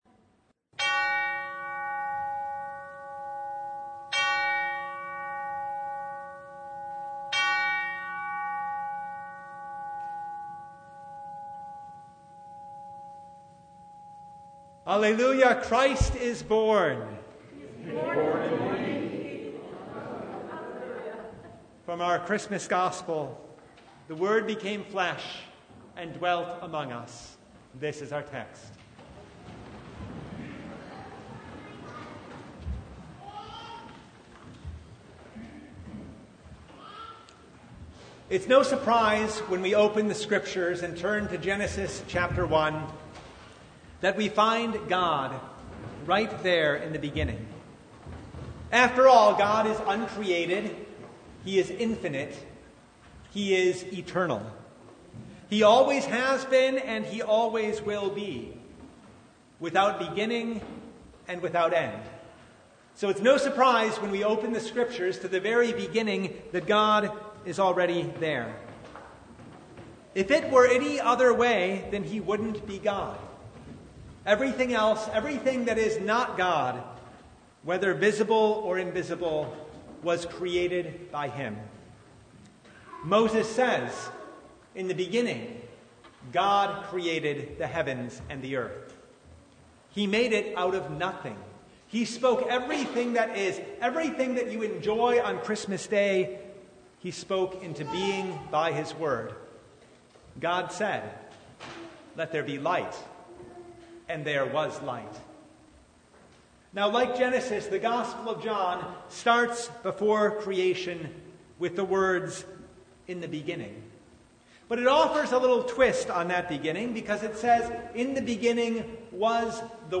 Service Type: Christmas Day
Sermon Only